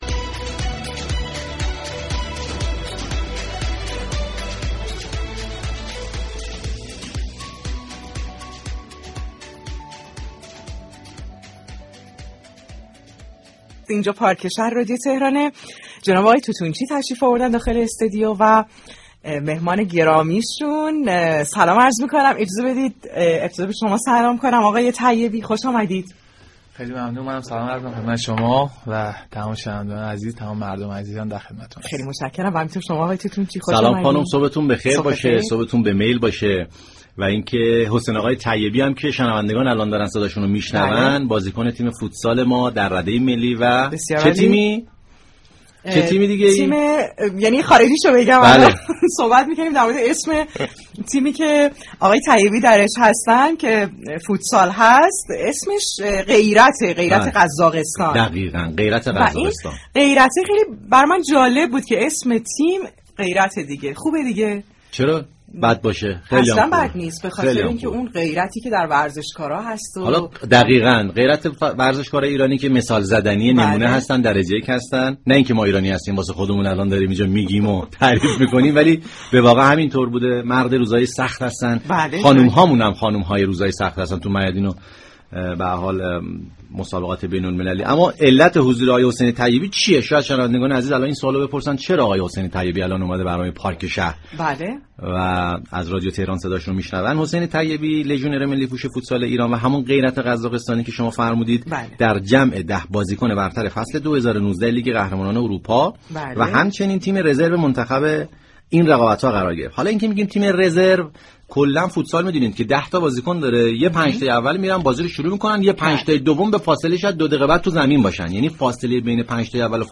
حسین طیبی، لژیونر ملی فوتسال ایران و بازیكن برتر فصل 2019 لیگ قهرمانان اروپا مهمان پارك شهر شنبه 5 بهمن رادیو تهران بود.